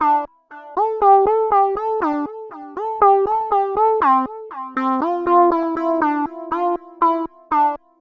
Session 14 - Euro Lead.wav